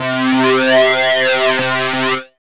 5804R SYNBUZ.wav